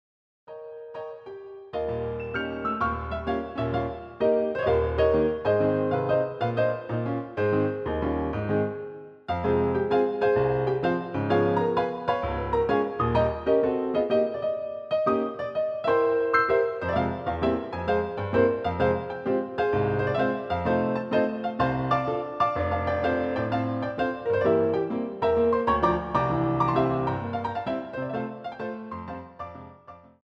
CD quality digital audio Mp3 file
using the stereo sampled sound of a Yamaha Grand Piano